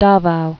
(dävou)